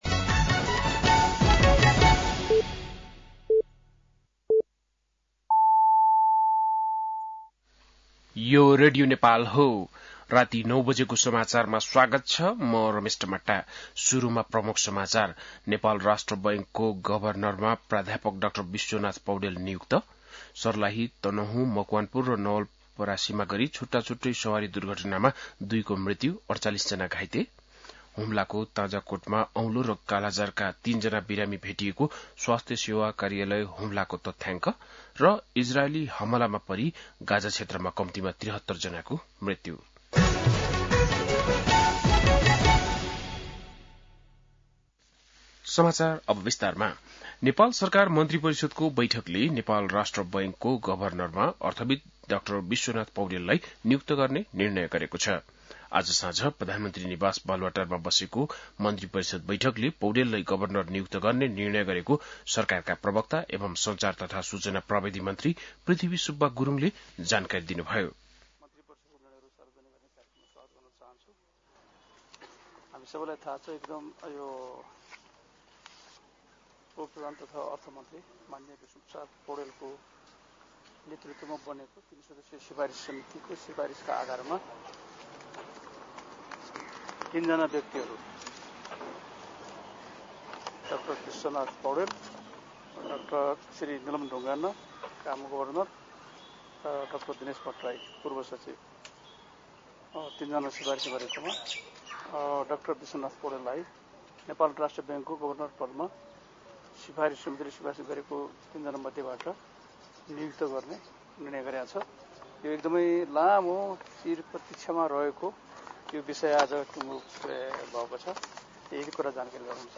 बेलुकी ९ बजेको नेपाली समाचार : ६ जेठ , २०८२
9-PM-Nepali-NEWS-02-06.mp3